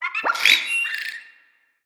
Sfx_creature_seamonkey_taunt_01.ogg